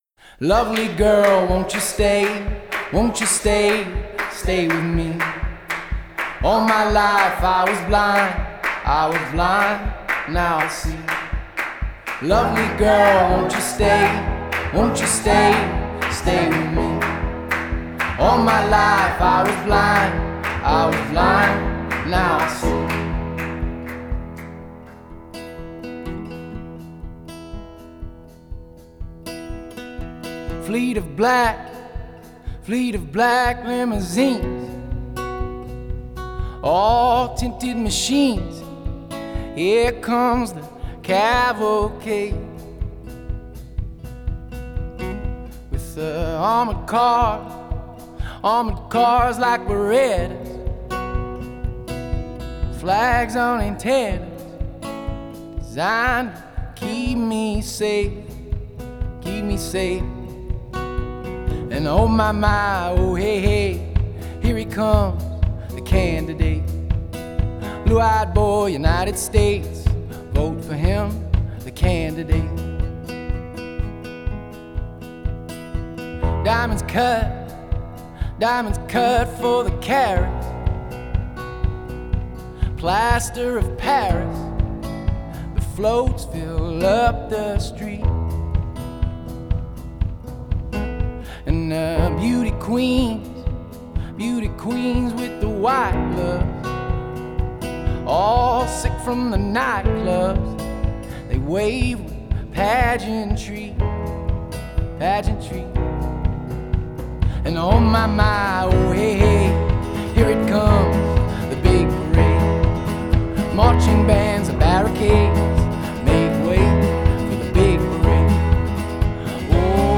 It’s a good time to be a folk music fan.